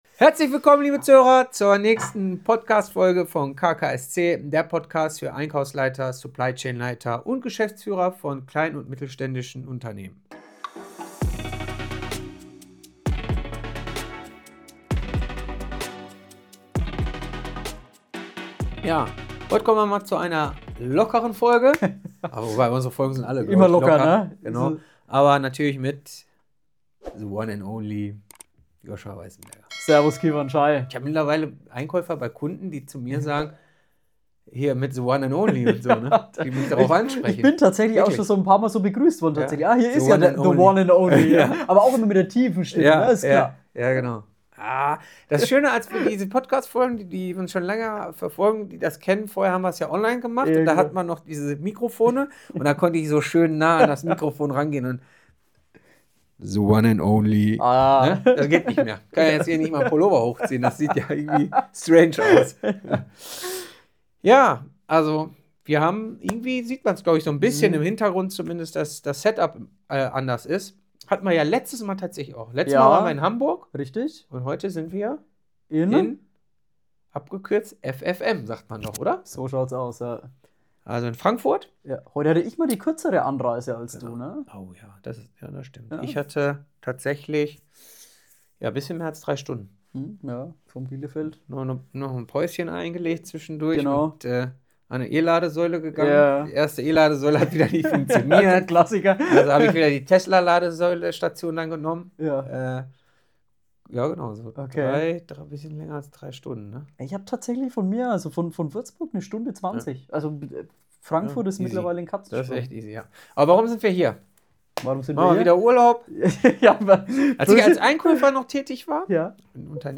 Kundenprojekts – live aus Frankfurt.